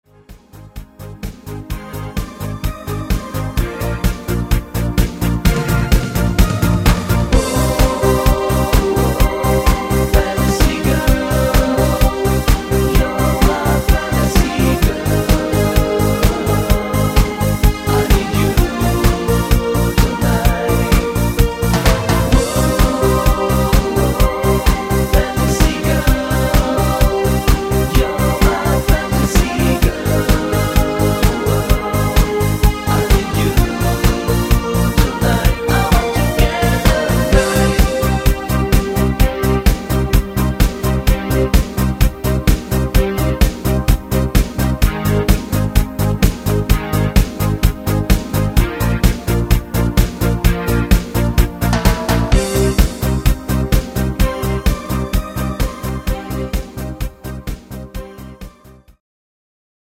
deutsche Freestyle Version